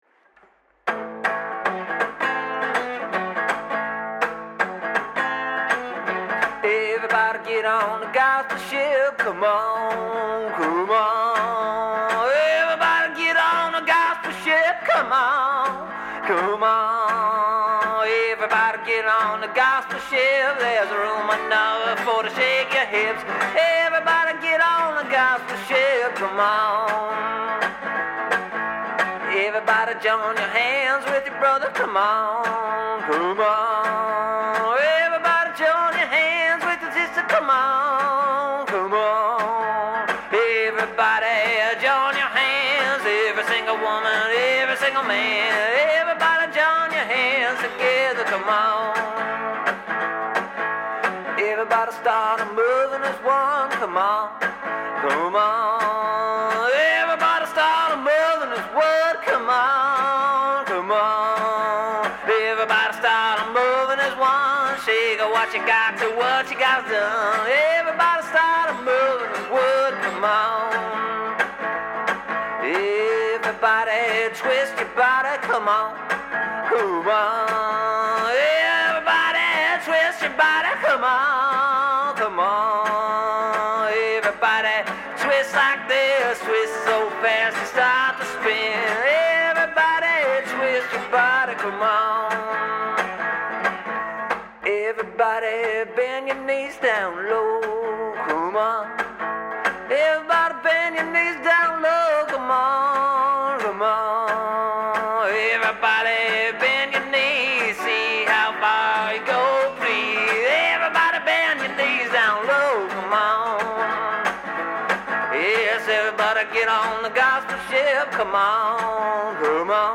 The whispering-singing-type part.